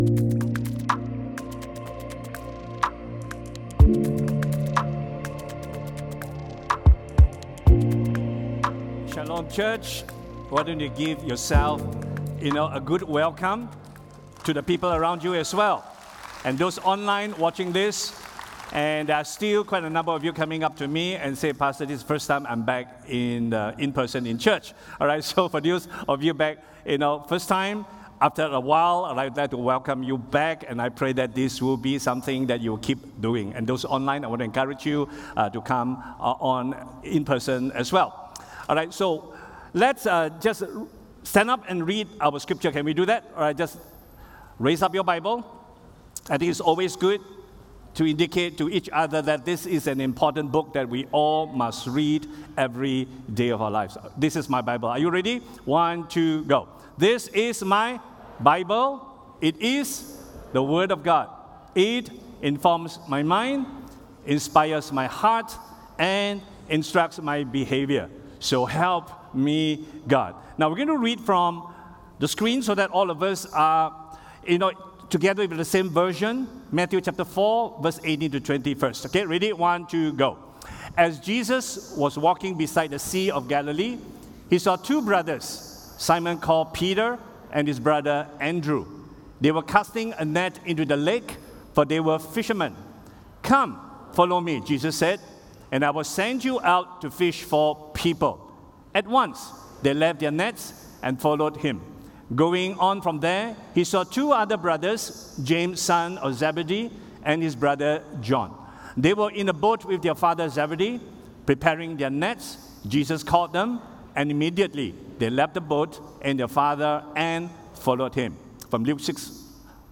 All Sermons What kind of Christian are you?